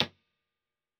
Hier eine blanke Room-IR.